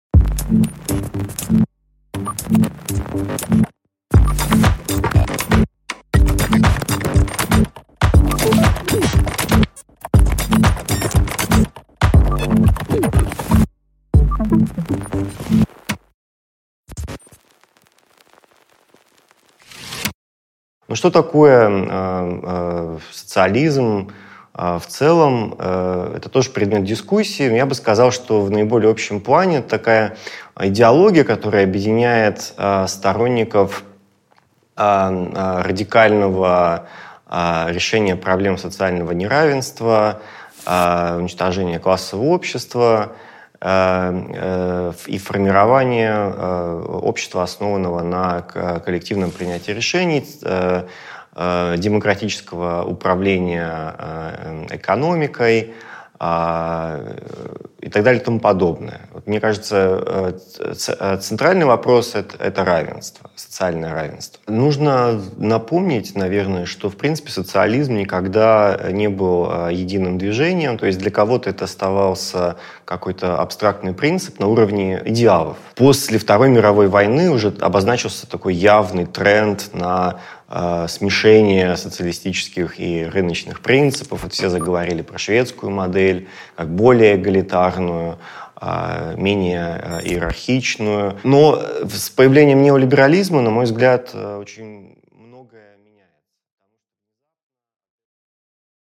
Аудиокнига Чтоб по-людски | Библиотека аудиокниг